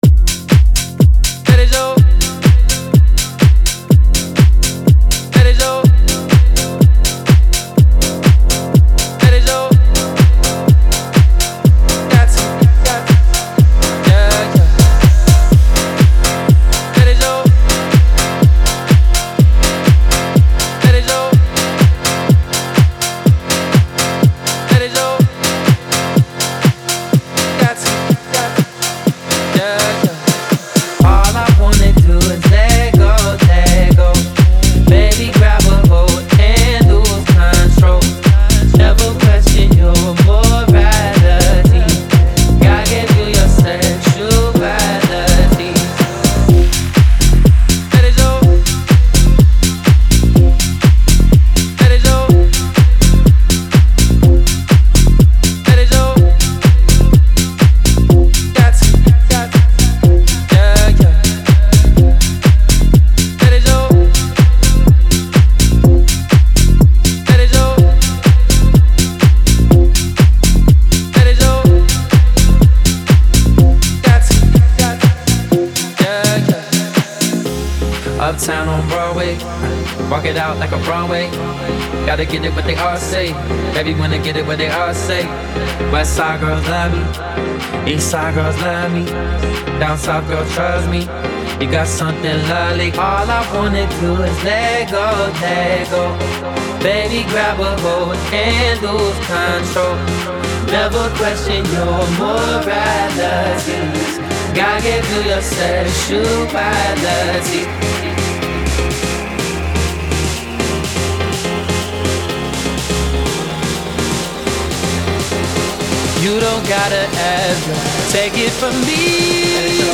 завораживающая комбинация хаус-ритмов и душевного вокала